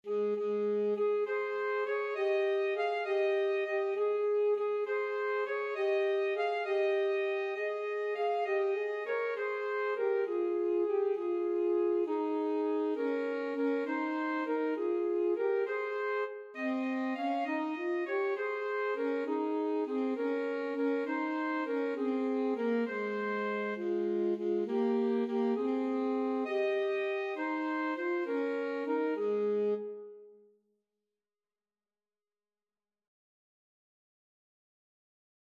Free Sheet music for Alto Saxophone Duet
Alto Saxophone 1Alto Saxophone 2
Ab major (Sounding Pitch) Eb major (French Horn in F) (View more Ab major Music for Alto Saxophone Duet )
6/8 (View more 6/8 Music)
Classical (View more Classical Alto Saxophone Duet Music)